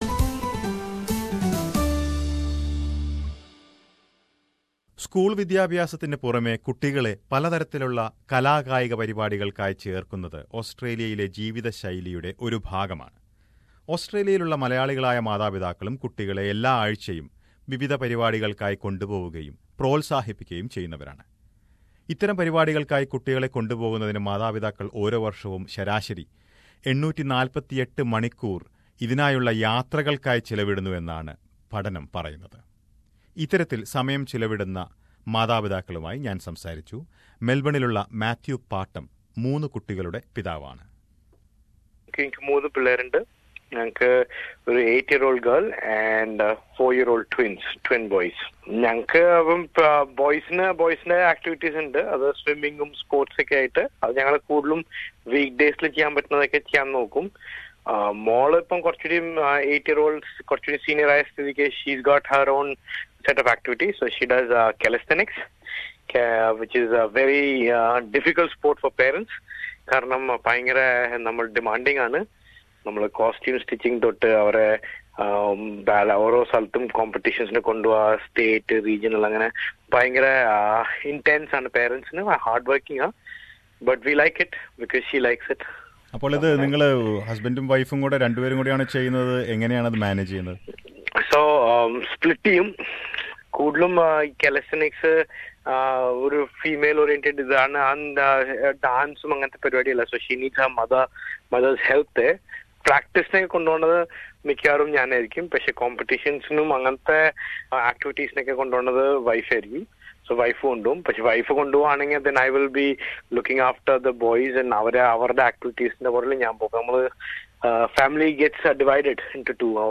But, a recent study says that many parents are overwhelmed by this activity especially when they spend a lot of time and money during the weekend sacrificing many other things. SBS Malayalam speaks to some parents and a psychologist about the findings of this study.